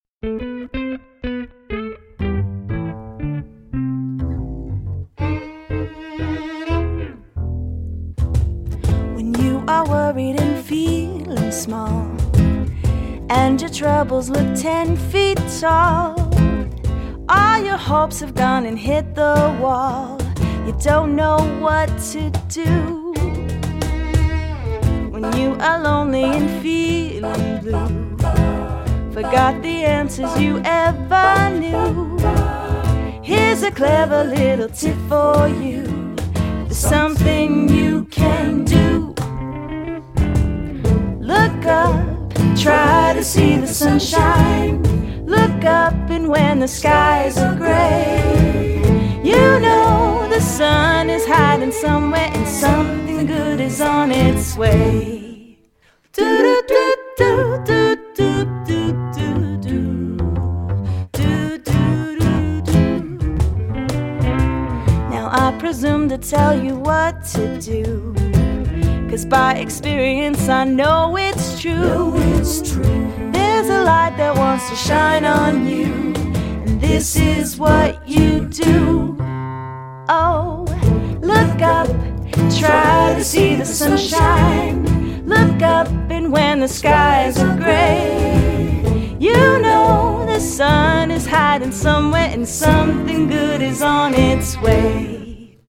" with its jazzy beat.